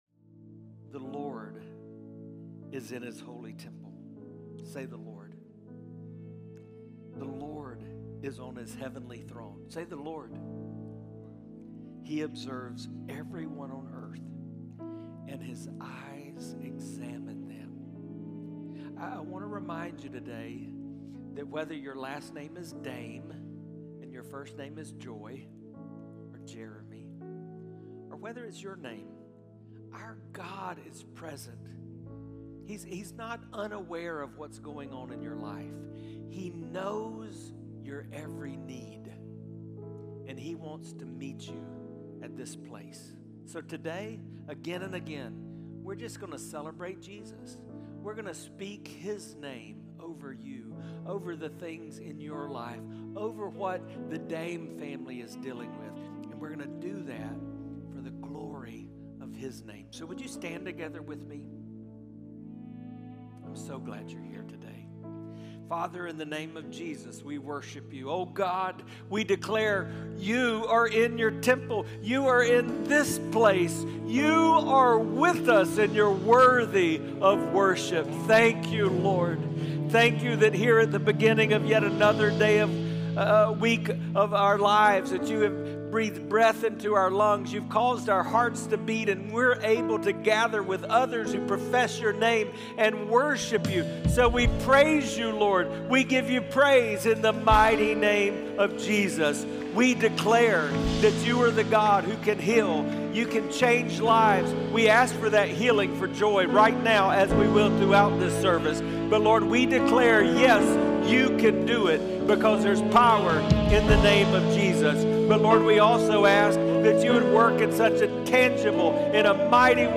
Prayer & Worship